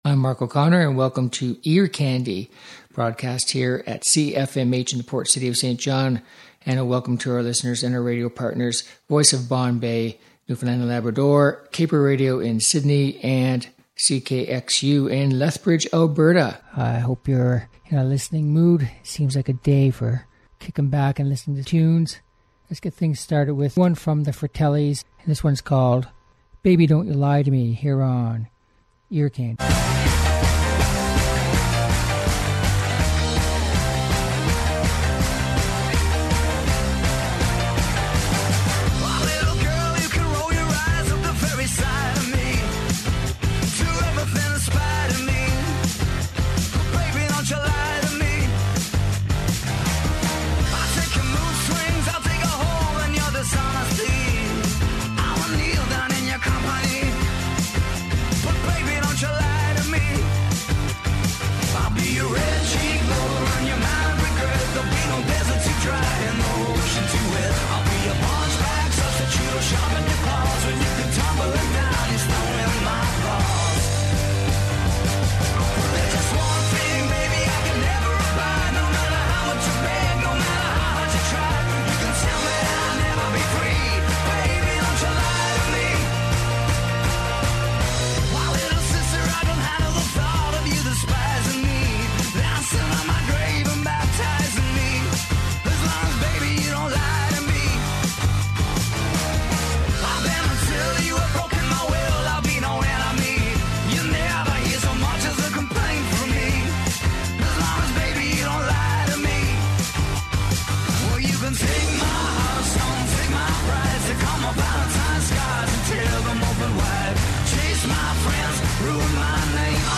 Uptempo Pop and Rock Songs